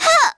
Artemia-Vox_Damage_02.wav